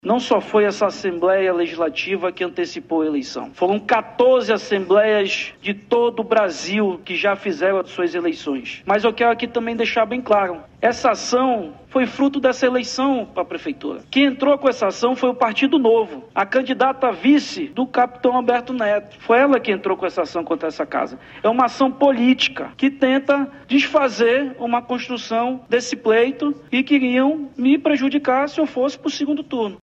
Em discurso na tribuna da Casa Legislativa, nesta terça-feira (29), Cidade declarou que a ação judicial teve o intuito de prejudicá-lo nas recentes eleições municipais, quando concorreu à prefeitura de Manaus.